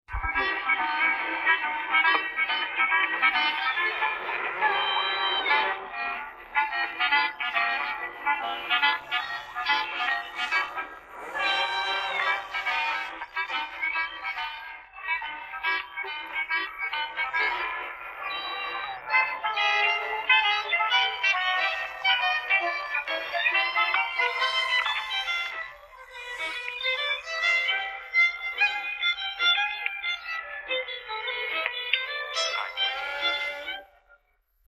Шуточная мелодия
Друзья!  Помогите, пожалуйста, опознать эту шуточную мелодию
Характерное сочетание кларнета и скрипки